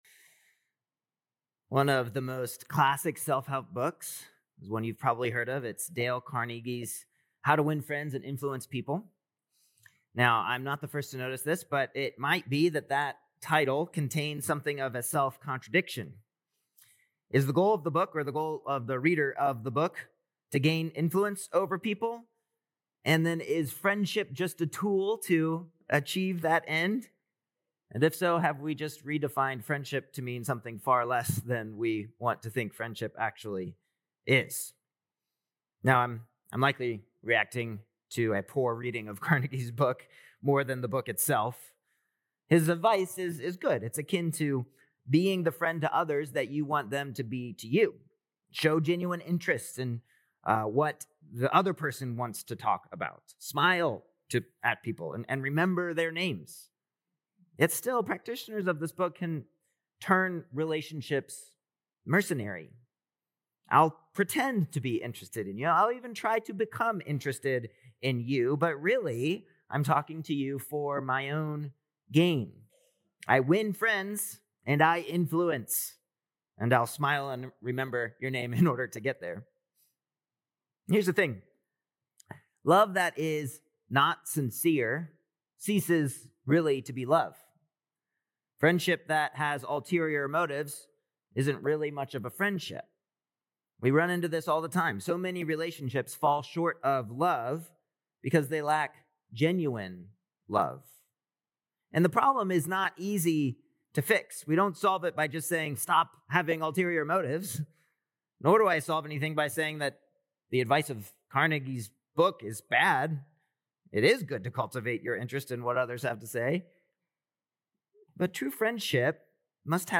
Apr 12th Sermon | 1 Peter 1:22-2:3